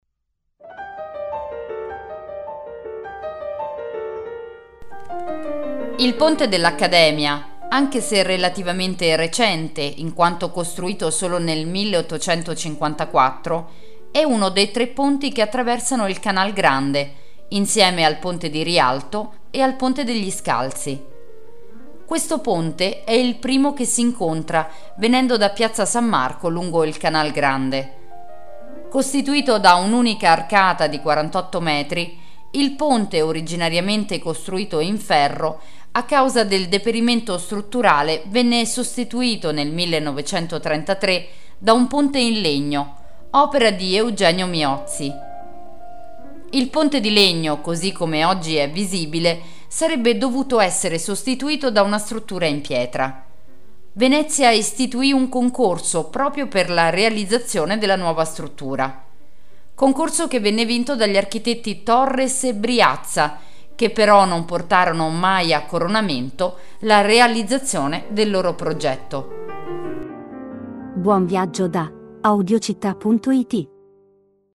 Audioguida Venezia - il Ponte dell'Accademia - Audiocittà